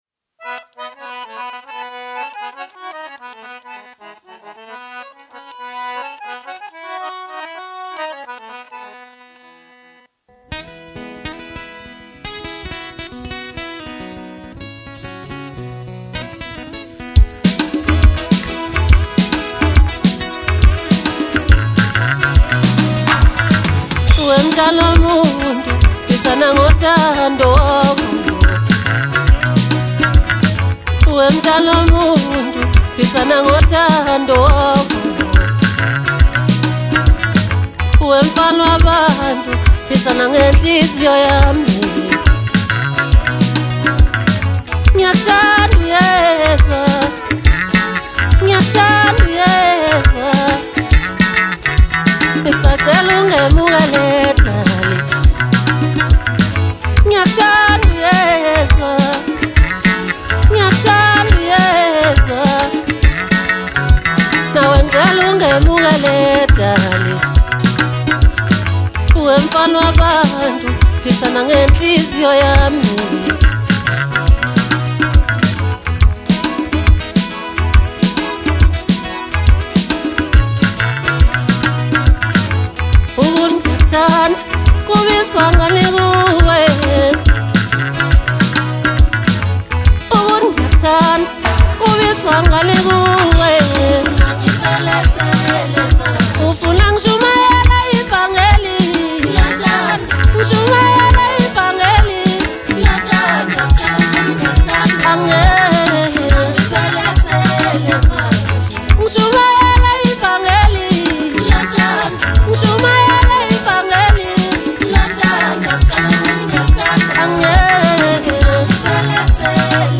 Home » Maskandi » Maskandi Music